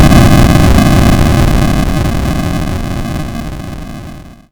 Retro Game Weapons Sound Effects – Retro-explosion-06 – Free Music Download For Creators
Retro_Game_Weapons_Sound_Effects_-_retro-explosion-06.mp3